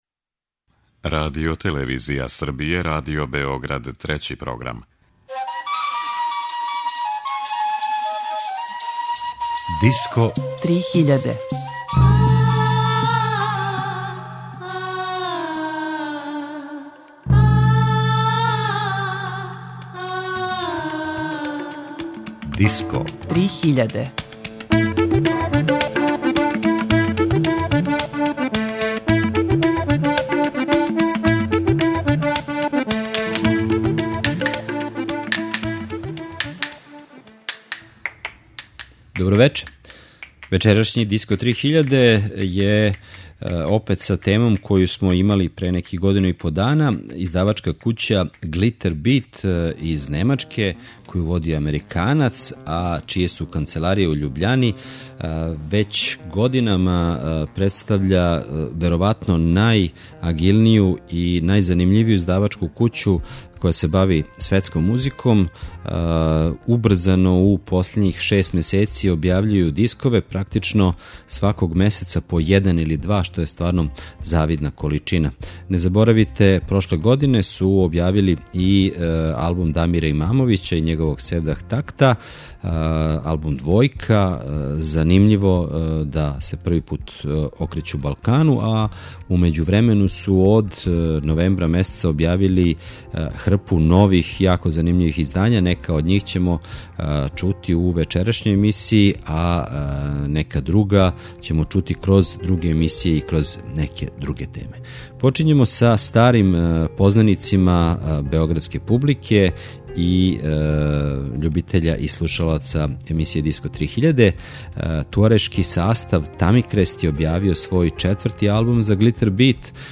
Disco 3000 је емисија посвећена world music сцени, новитетима, трендовима, фестивалима и новим албумима.